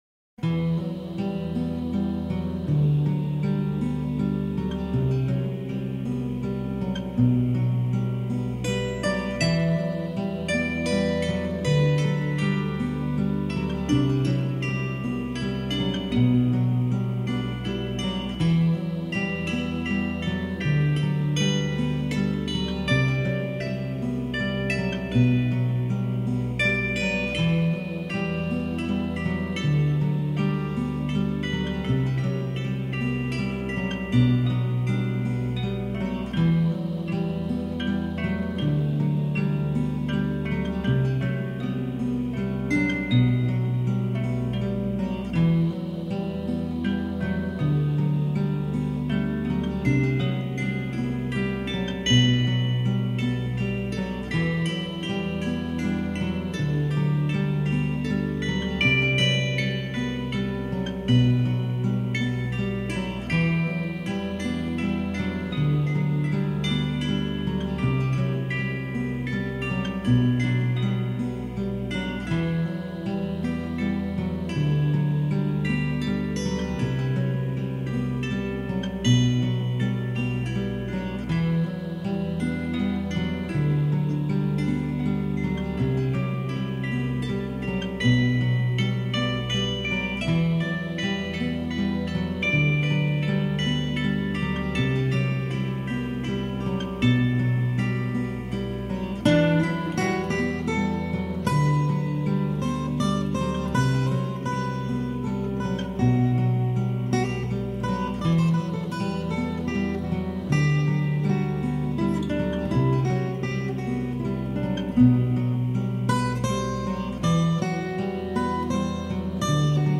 Folk
World music
Country-rock